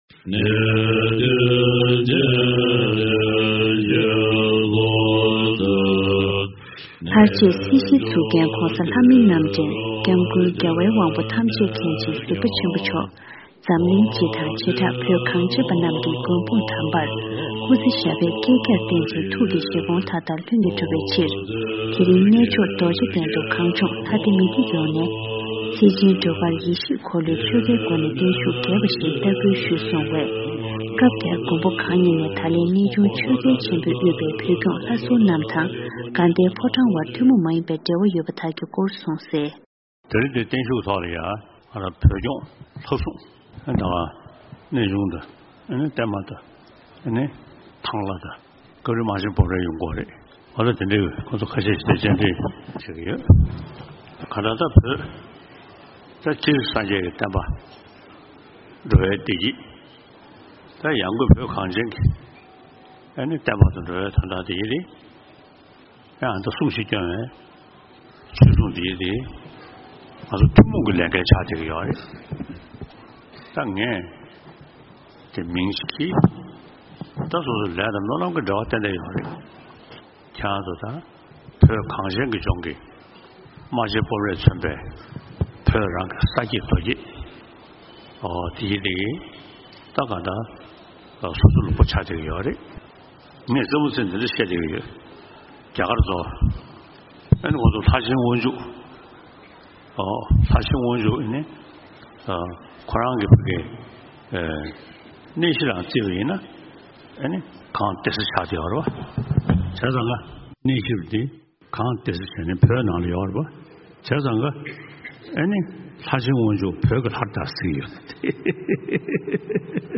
རྡོ་རྗེ་གདན་ནས་གནས་ཚུལ་ཞིབ་ཏུ་ངོ་སྤྲོད་ཞུས་ཡོད།།